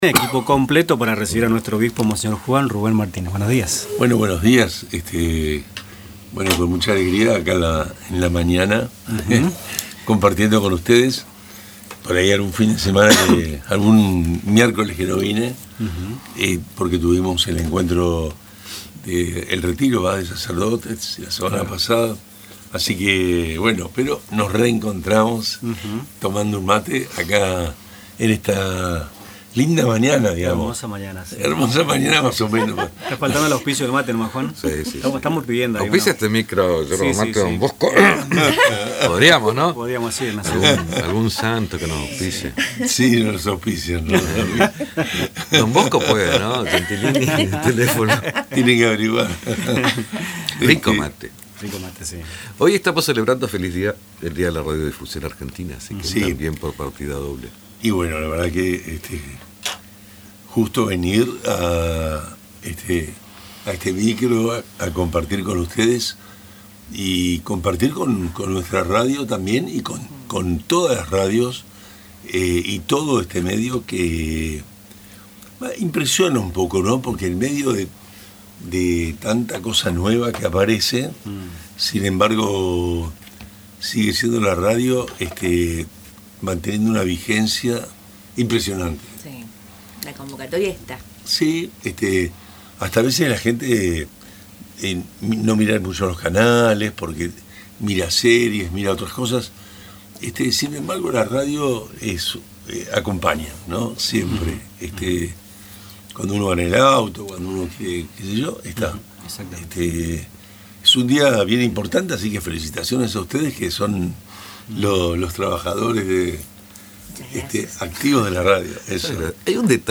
El obispo diocesano, monseñor Juan Rubén Martínez, compartió en La Creíble FM 105.9 una reflexión en el marco del Día de la Radiodifusión Argentina.